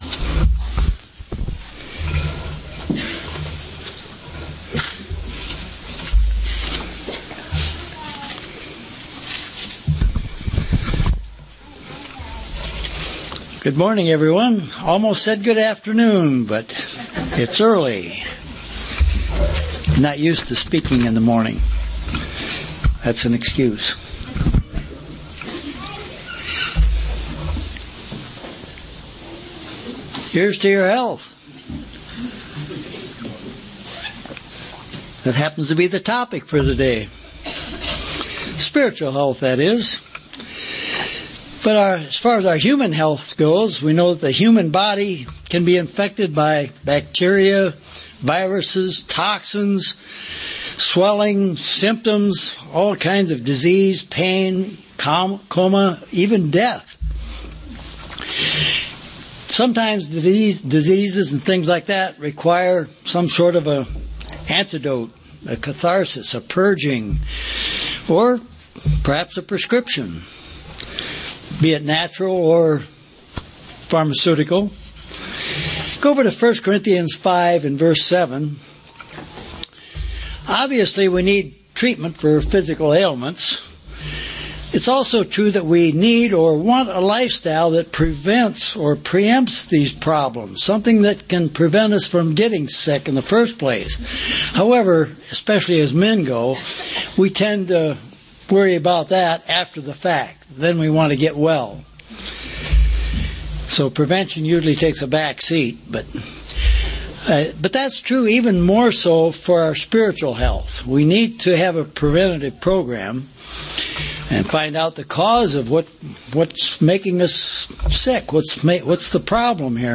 UCG Sermon Studying the bible?
Given in St. Petersburg, FL